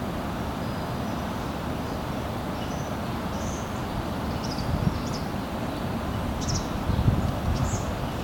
Observação BirdNET - Alvéola-branca - 2022-02-27 08:55:05
Alvéola-branca observado com o BirdNET app. 2022-02-27 08:55:05 em Lisboa